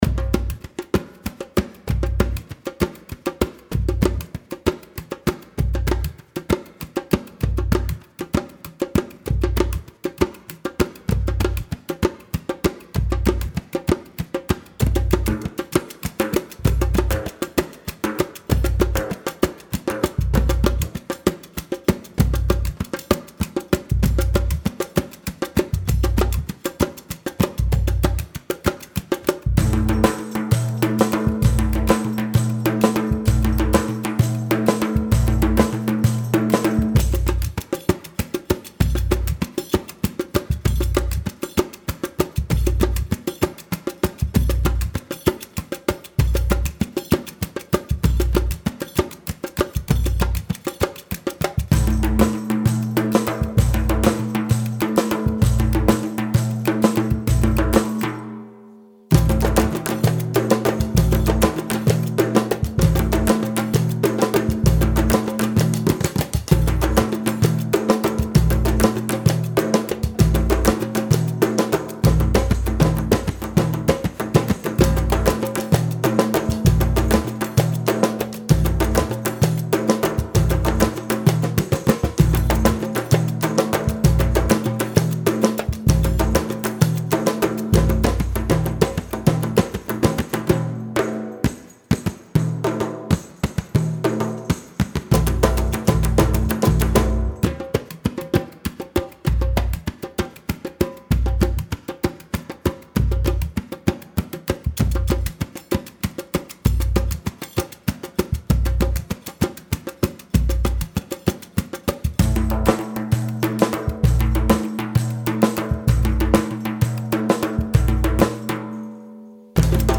Percussionist, Composer, Performer.
Recorded at Xo’s Office Studio
Mixed at Baysound Studio